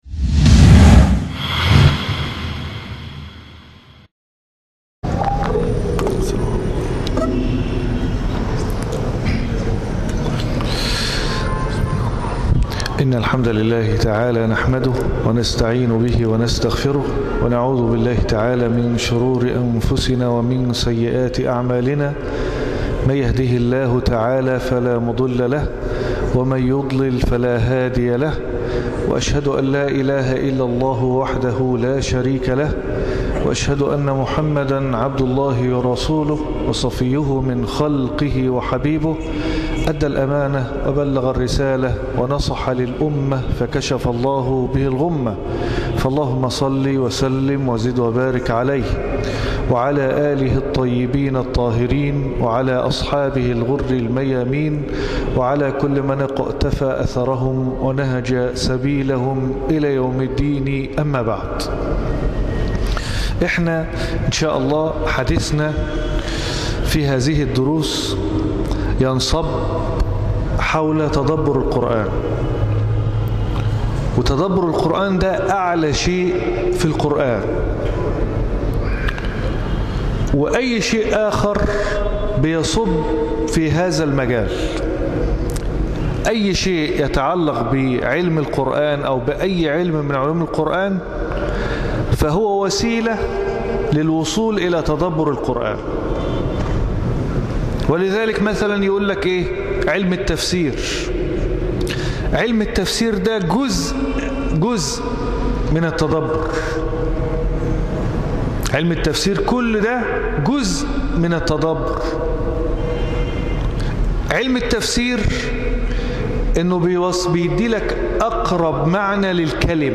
سلسلة كيف تتلذذ بقراءة القرآن ، الدرس الأول
مسجد الجمعية الشرعية بالمنصورة